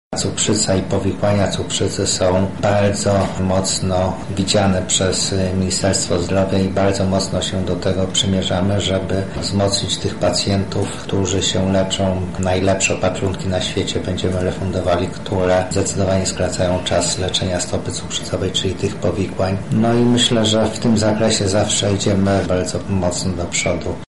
O wyzwaniach resortu mówi wiceminister zdrowia, Marcin Miłkowski: